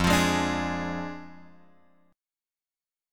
F Minor 6th
Fm6 chord {1 x 3 1 3 4} chord